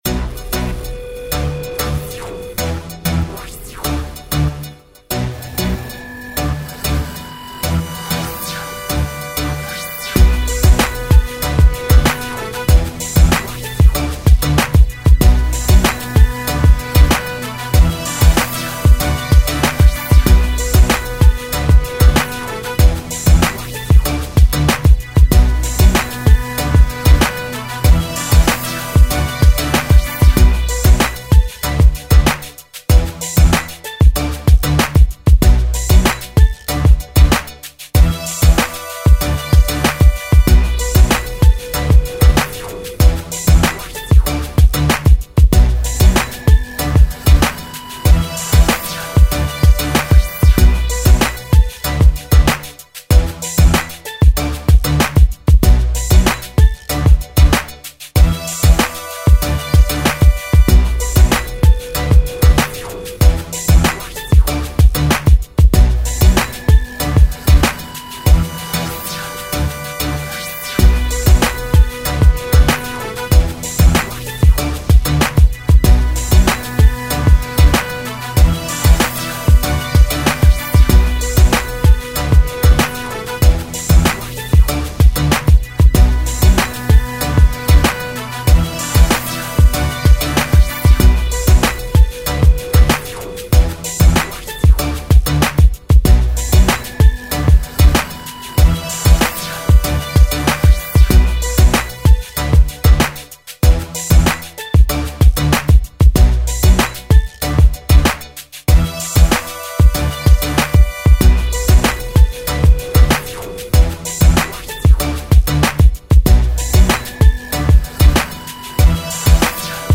Club